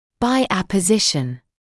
[baɪ ˌæpə’zɪʃn][бай ˌэпэ’зишн]путем присоединения новых слоев (напр., кости)